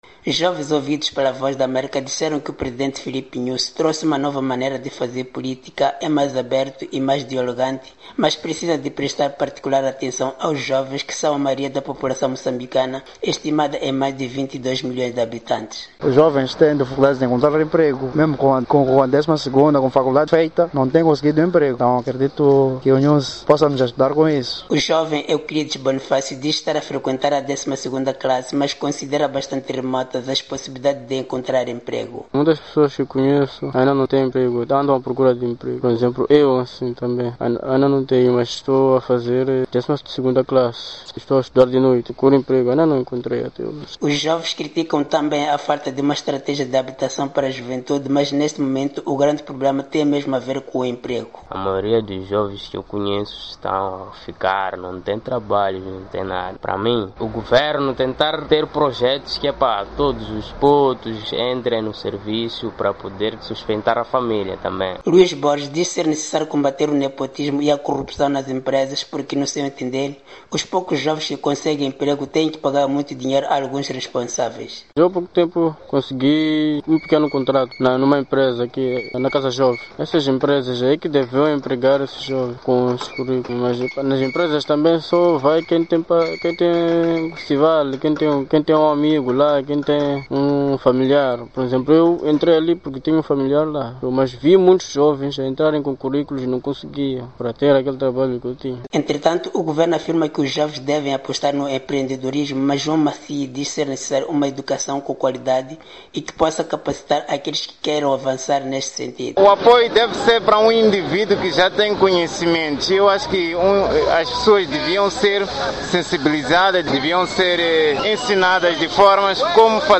Jovens ouvidos pela VOA afirmaram que o Presidente Nyusi trouxe uma nova maneira de fazer política, é mais aberto e mais dialogante mas precisa de prestar particular atenção aos jovens, que são a maioria da população moçambicana, estimada em mais de 22 milhões de habitantes.